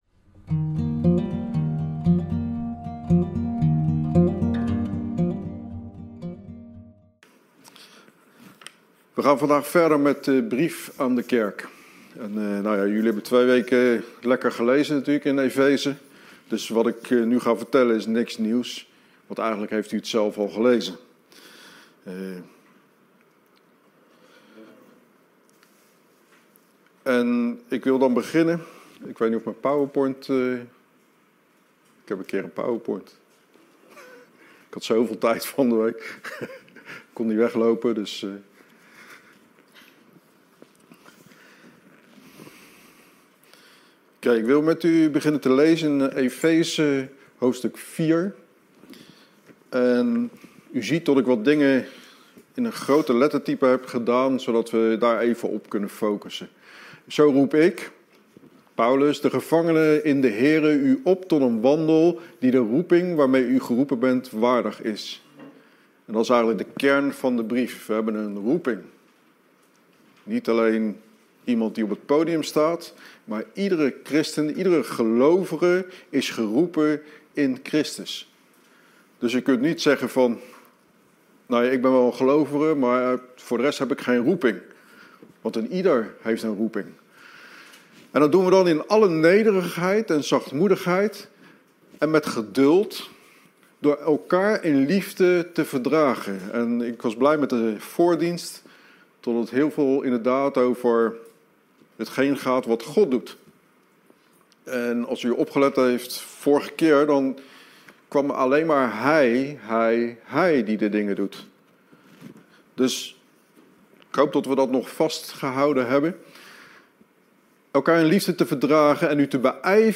Preken